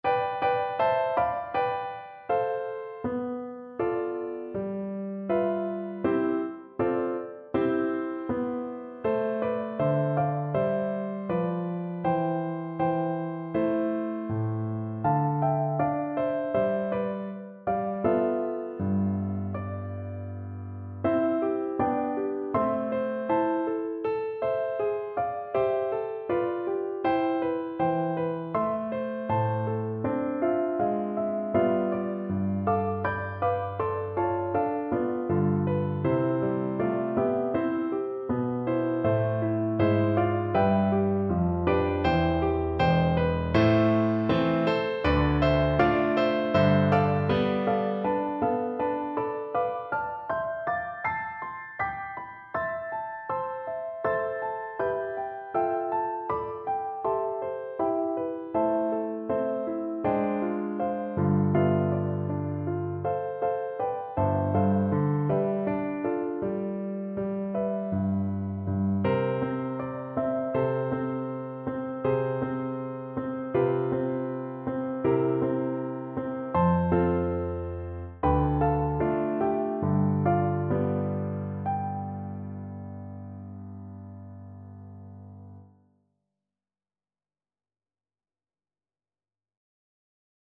2/4 (View more 2/4 Music)
Moderato =80
world (View more world Flute Music)